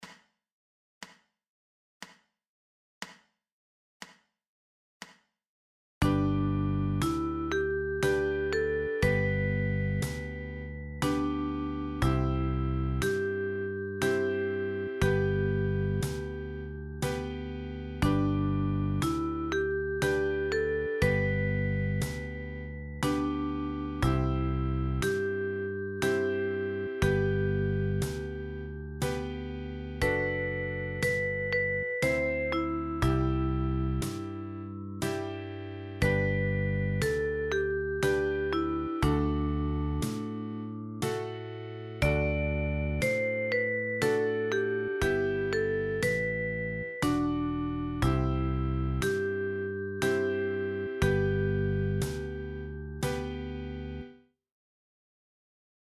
Der Song ist in einem langsamen Tempo aufgenommen. Die Instrumentierung wurde so gewählt dass sich der Klang möglichst wenig mit dem des eigenen Instrumentes vermischt.